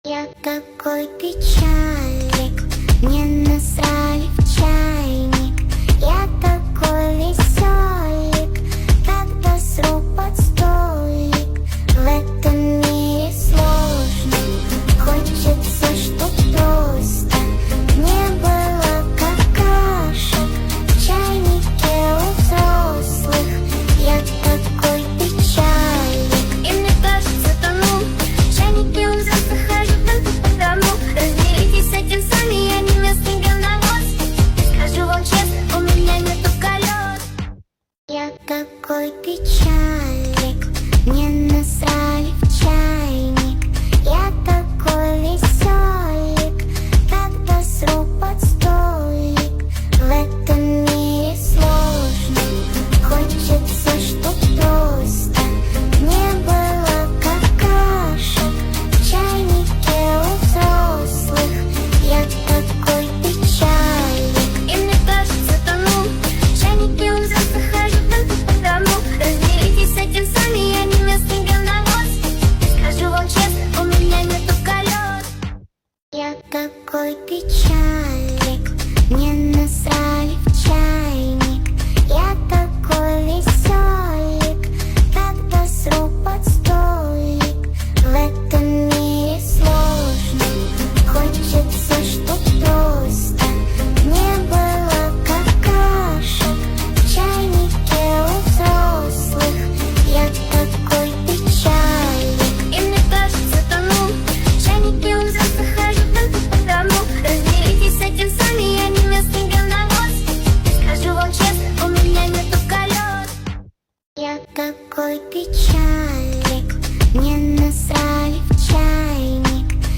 Качество: 240 kbps, stereo